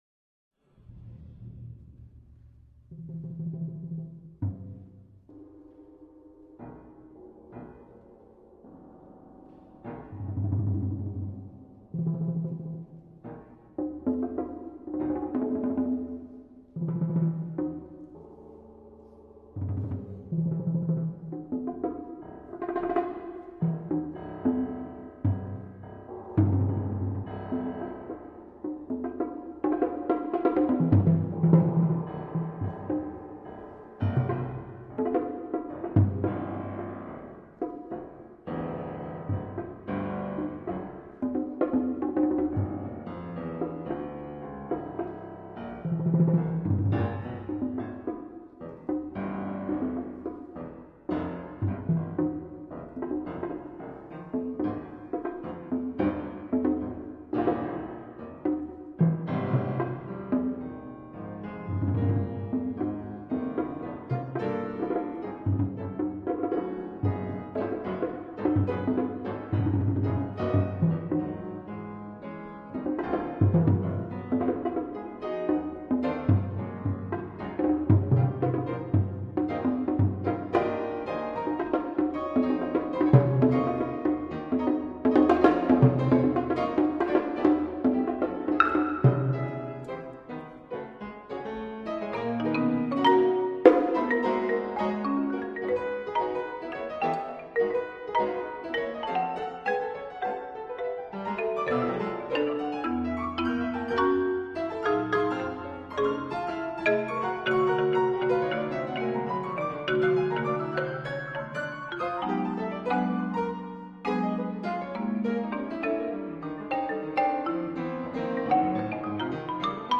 for piano and percussion.